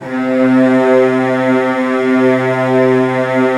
CELLOS DN3 S.wav